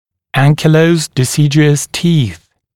[ˈæŋkɪˌləuzd dɪ’sɪdjuəs tiːθ] [-st][ˈэнкиˌлоузд ди’сидйуэс ти:с] [-ст]пораженные анкилозом молочные зубы